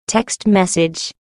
Category: Message Tones